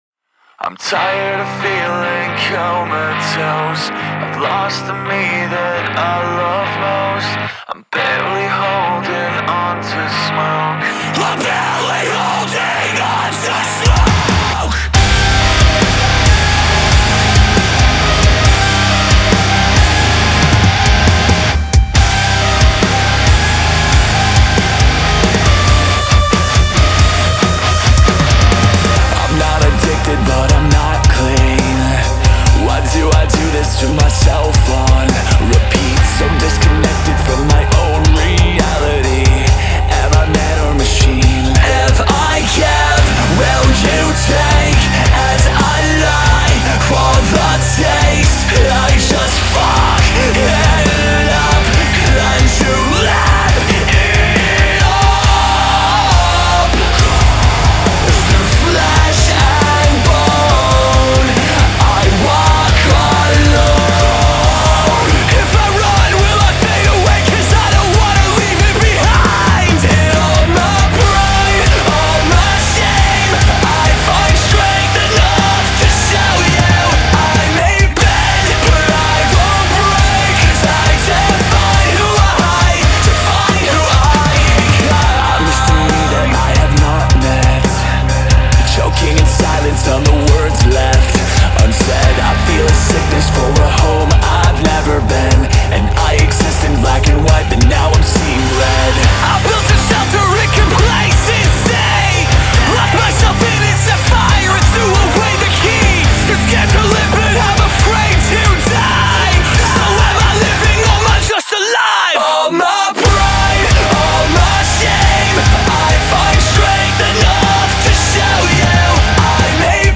Genre : Rock, Metal